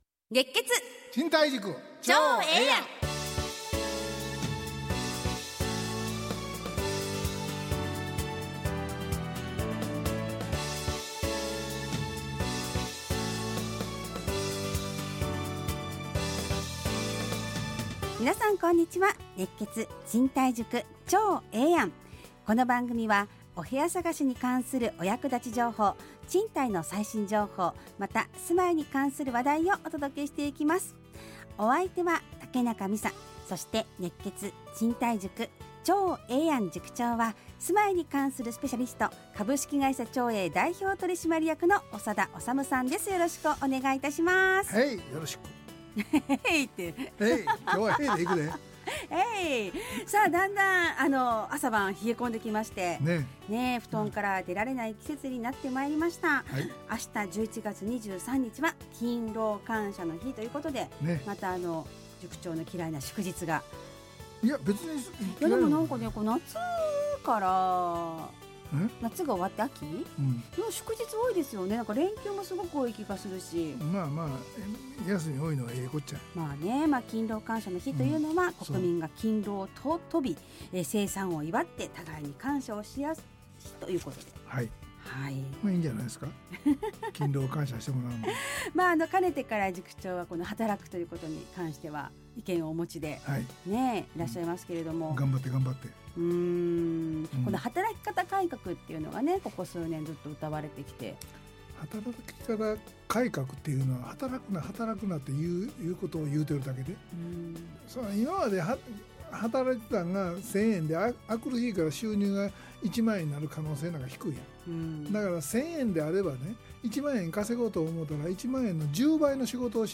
ラジオ放送 2024-11-25 熱血！